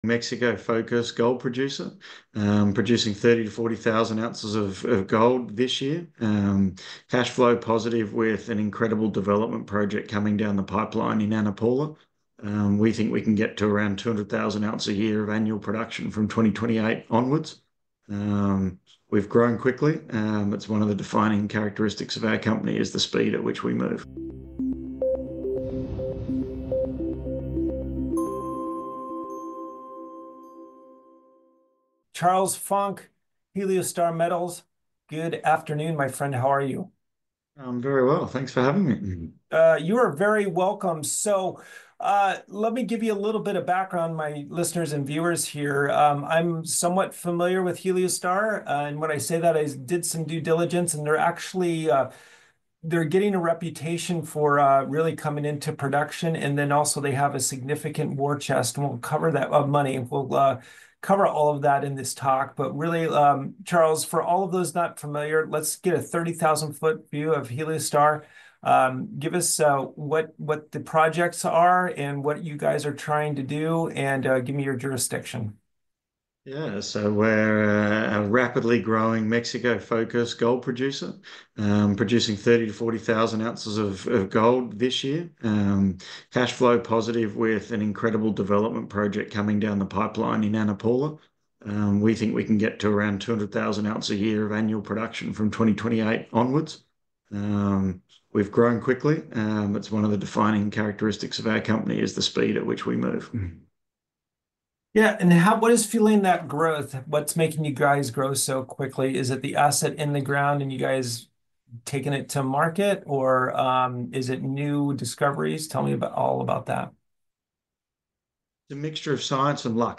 Exclusive Interview